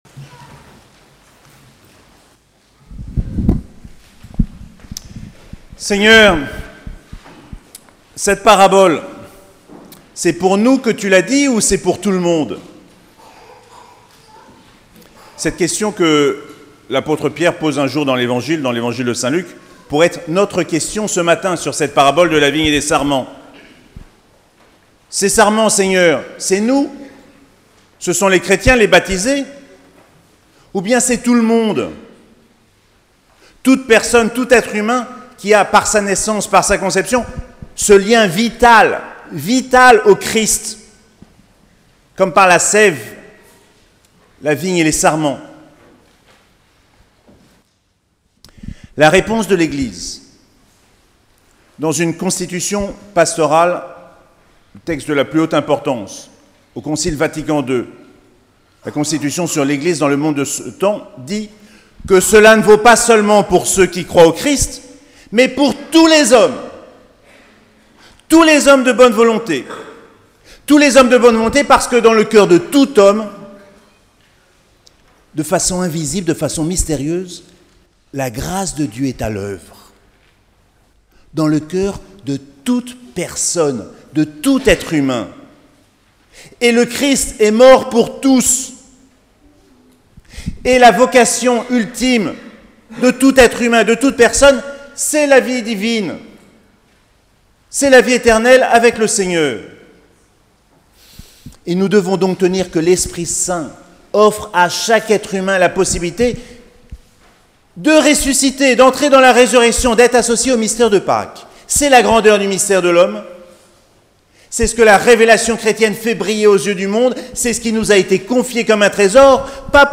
5ème dimanche de Pâques - 29 avril 2018